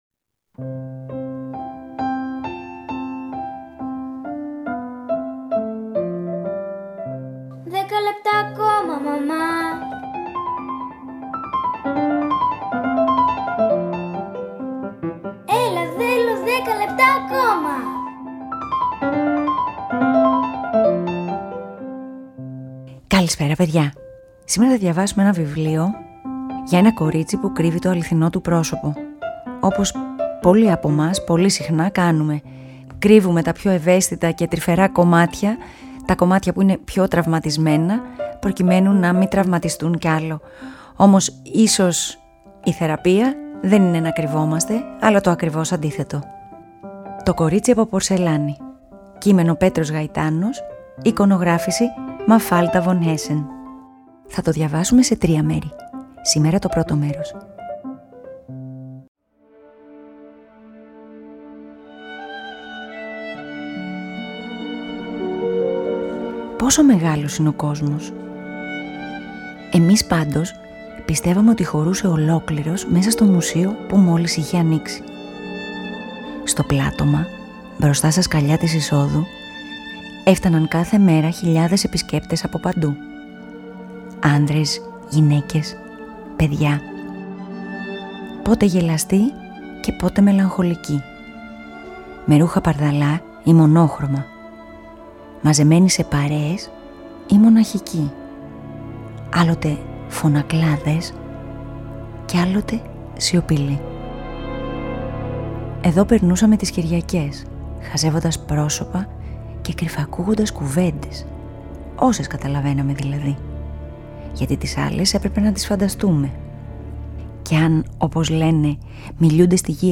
Μια τρυφερή και συμβολική αφήγηση, που με απλό και κατανοητό τρόπο, εξερευνά τους παιδικούς φόβους, για να καταλήξει στο ξεπέρασμά τους. Αφήγηση-Μουσικές επιλογές: